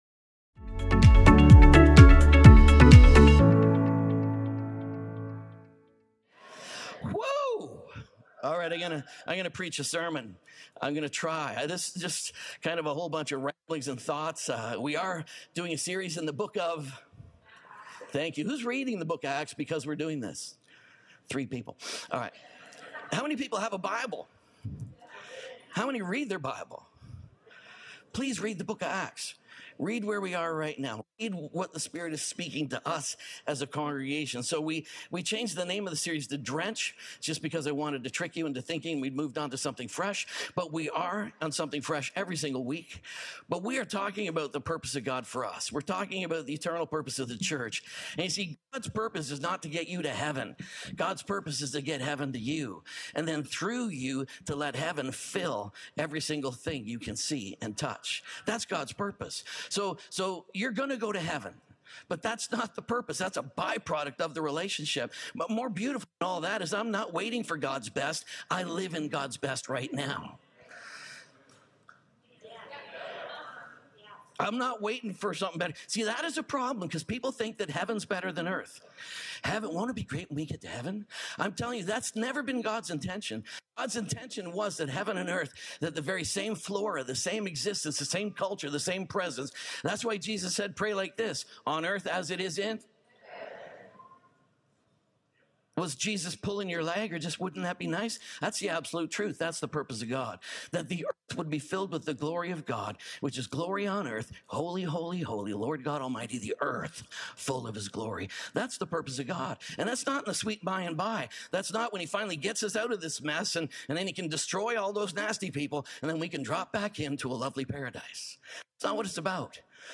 WHEN PUSH COMES TO PRAYER III | SERMON ONLY .mp3